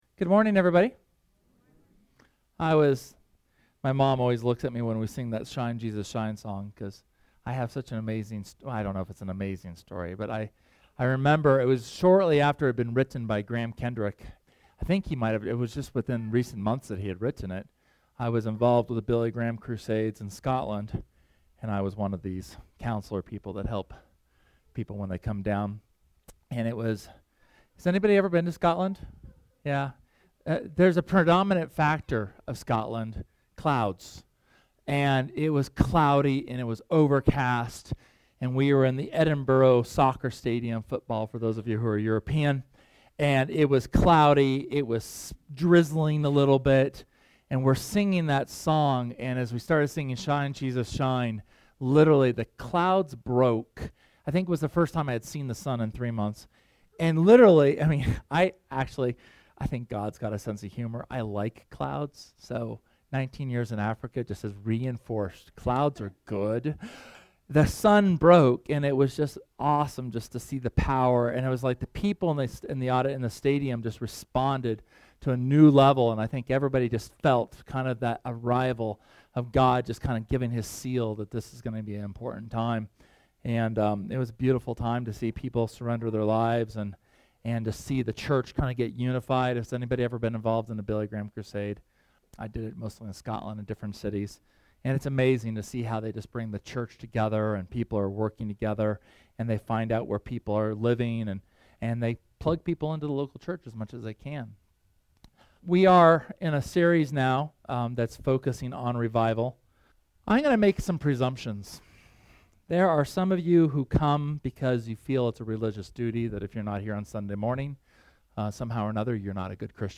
SERMON: Revival (2)
Second sermon on reformation and revival on July 31st. God rewards His people's spiritual hunger with His presence.